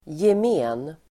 Uttal: [jem'e:n]